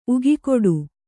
♪ ugikoḍu